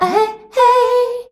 AHEHEY  G.wav